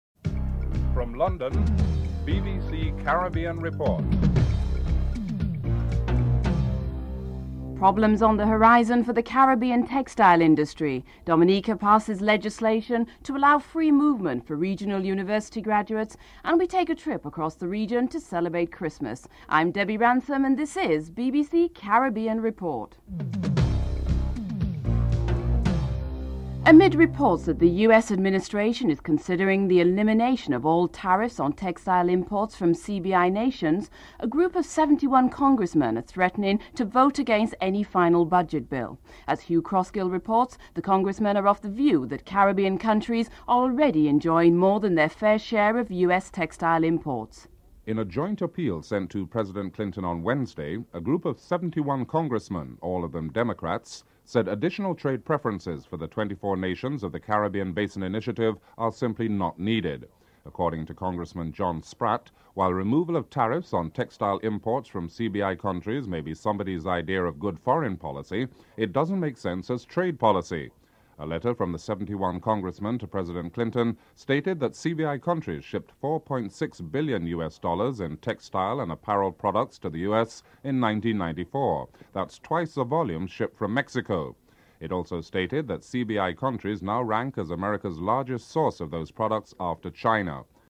This report examines the US Administration plans to eliminate all tariffs on textile imports from CBI nations.